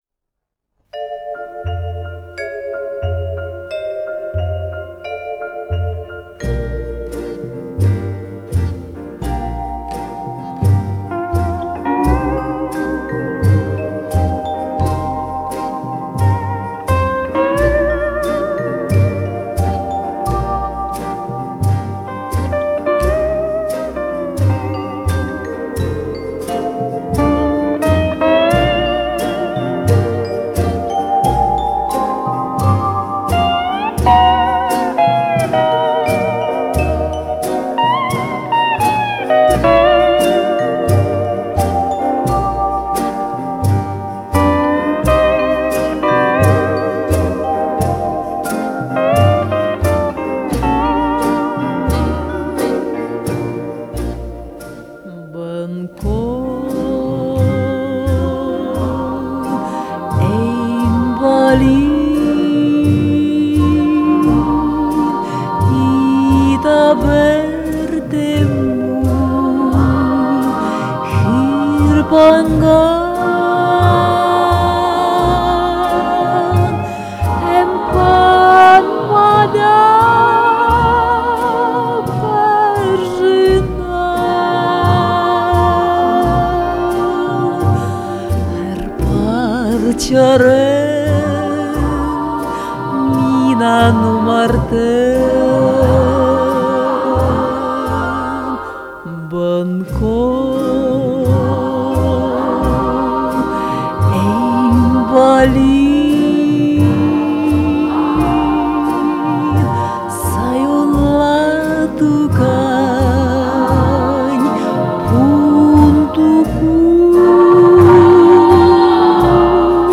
Она пела, а мы ей подджазивали